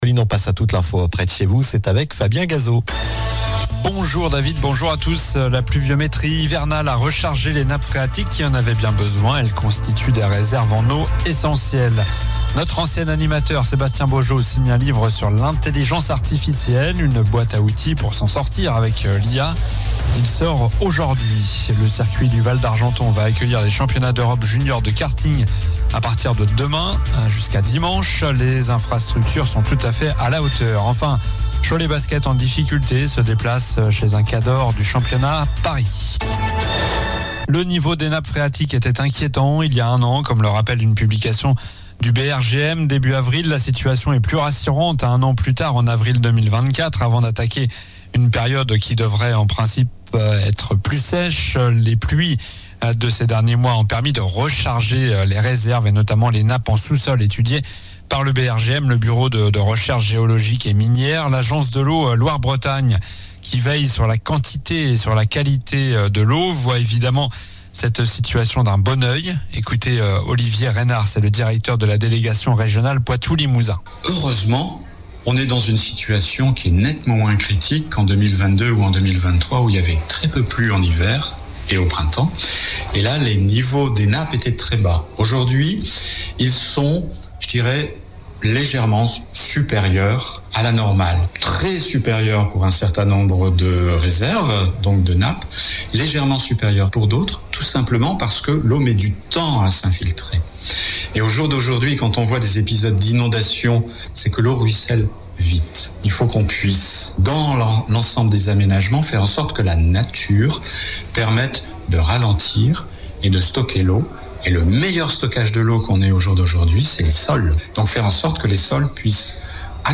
Journal du mercredi 24 avril (midi)